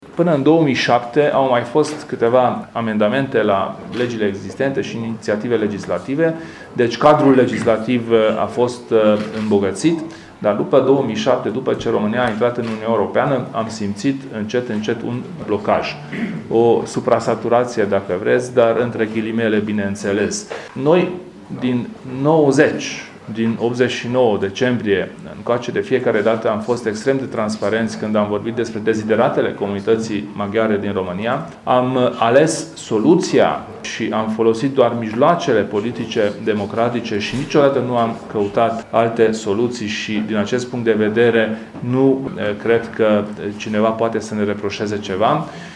Președintele UDMR, Kelemen Hunor, a declarat la Tîrgu-Mureș că, de la schimbarea Constituției din 2003, pentru maghiari nu a existat o modificare legislativă puternică, în afară de Legea educației din 2011.